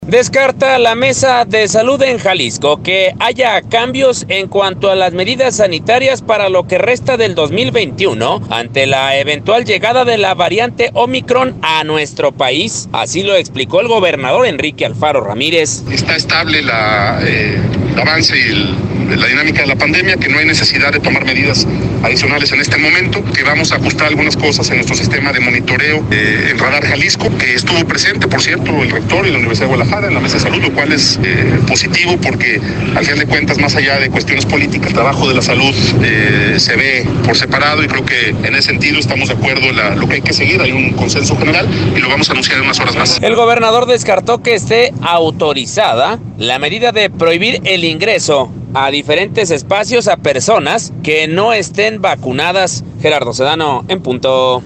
Descarta la Mesa de Salud en Jalisco, que haya cambios en cuanto a las medidas sanitarias para lo que resta del 2021, ante la eventual llegada de la variante Ómicron a nuestro país, así lo explicó el gobernador Enrique Alfaro: